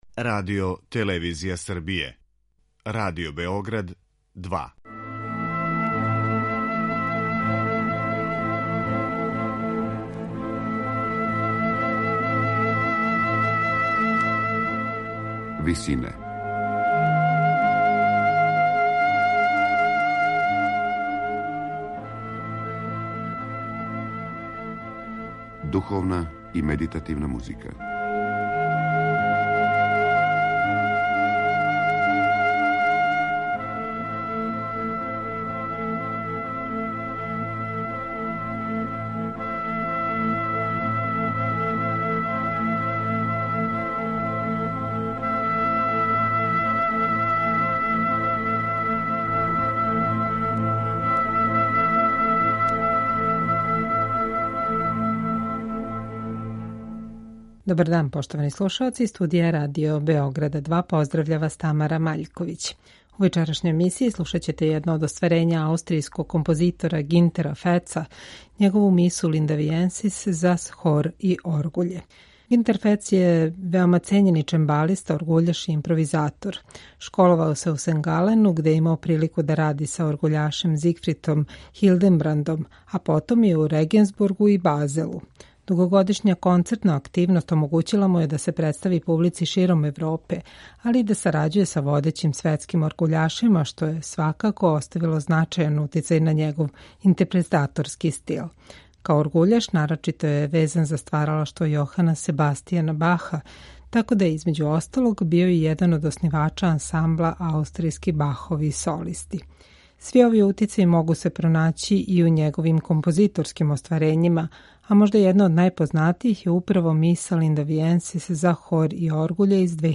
Архаични звуци хорова и оргуљска пратња красе ово дело